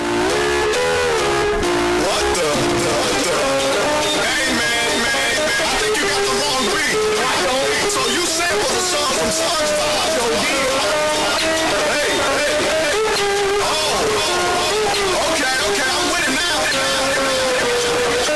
Beanos Earrape
Memes